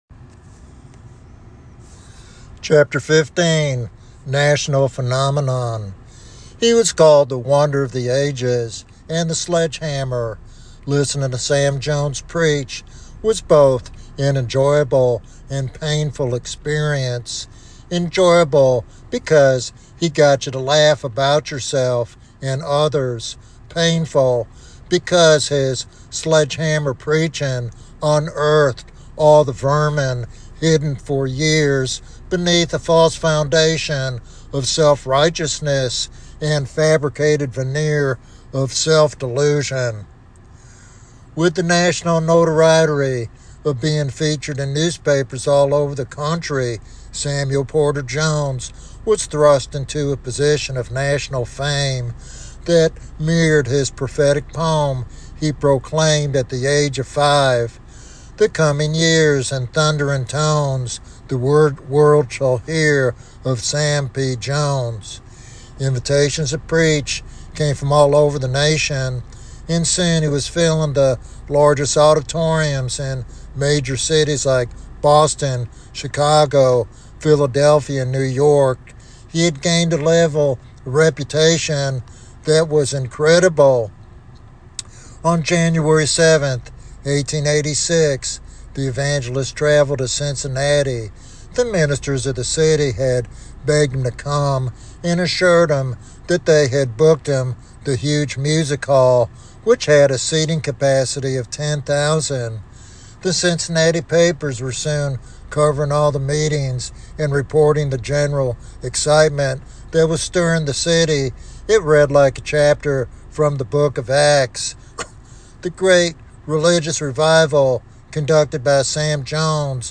In this biographical sermon